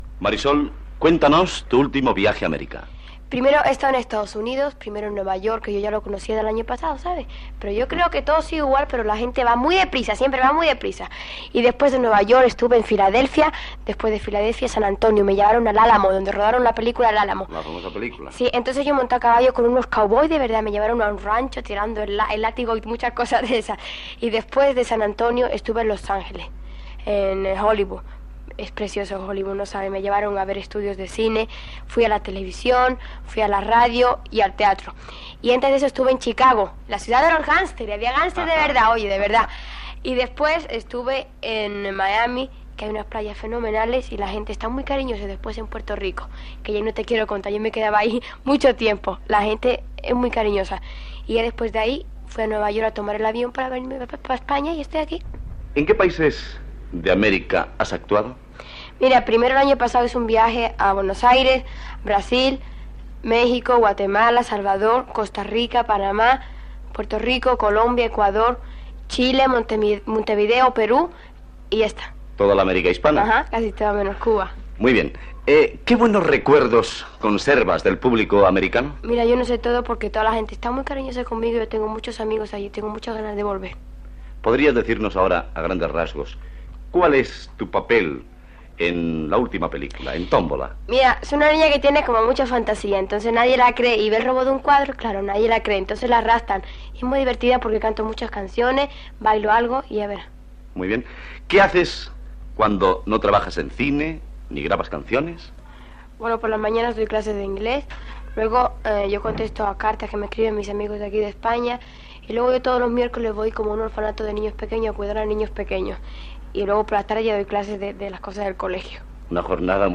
Entrevista a Pepa Flores "Marisol" quan va tornar d'un viatge a Amèrica.
Informatiu